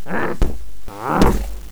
mummy_attack12.wav